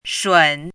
chinese-voice - 汉字语音库
shun3.mp3